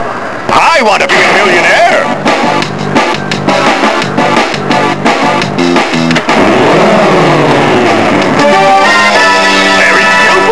Download 114Kb Oppstartsmusikk